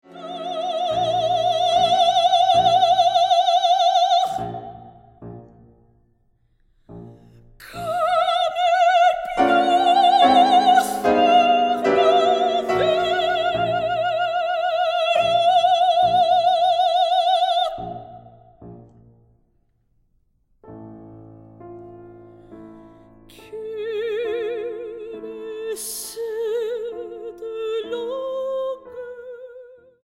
para voz y piano.